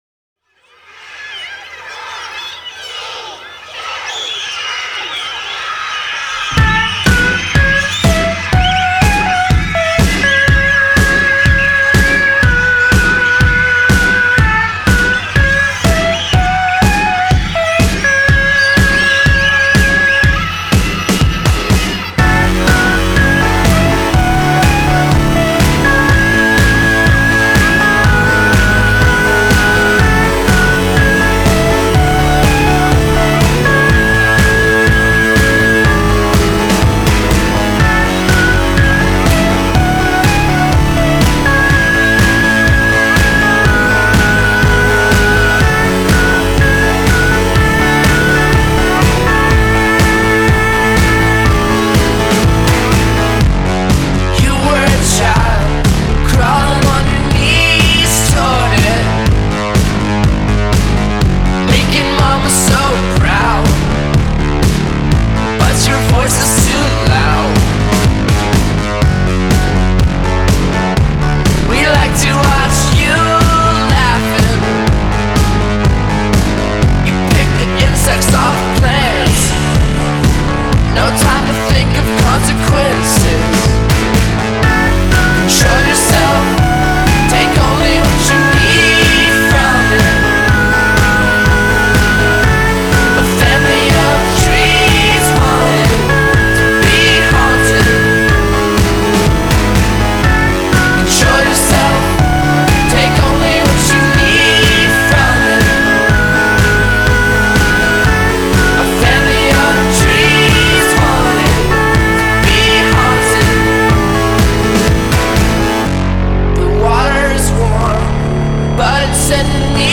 Pop, Rock